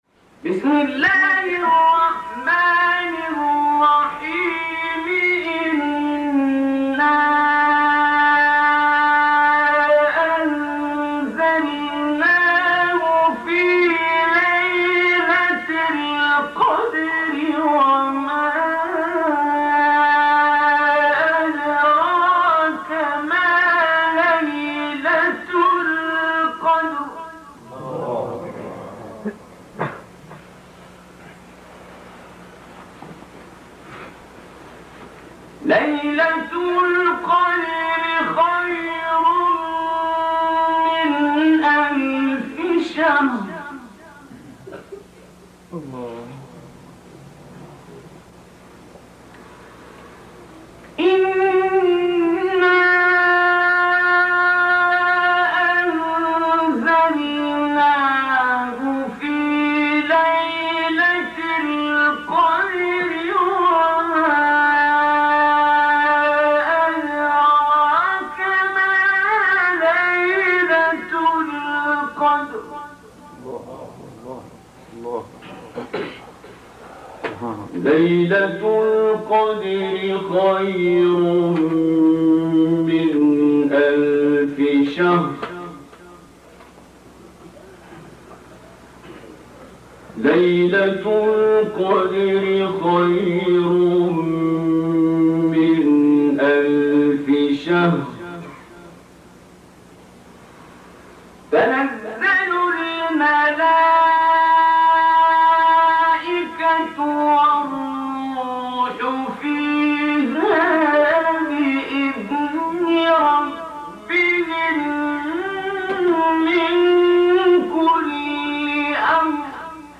تلاوت قرآن عبدالباسط سوره قدر | نغمات قرآن | دانلود تلاوت قرآن